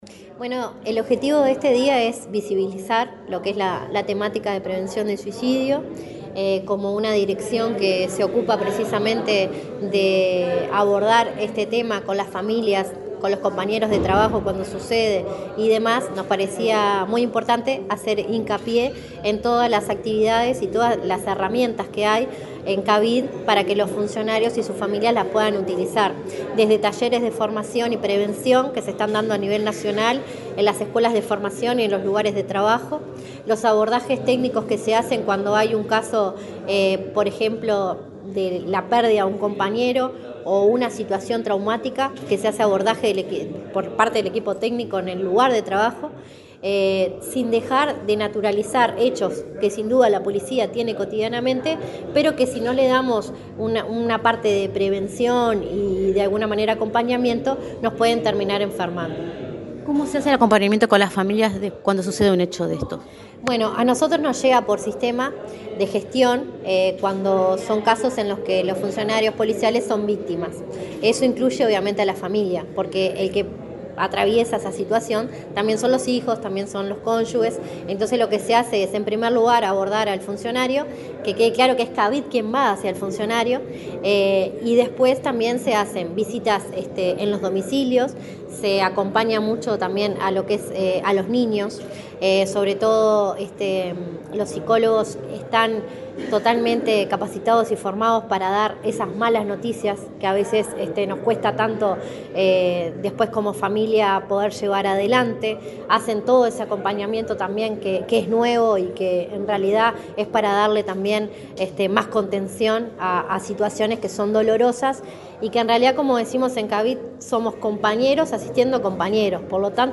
Entrevista a la directora de Cavid del Ministerio del Interior, Patricia Rodríguez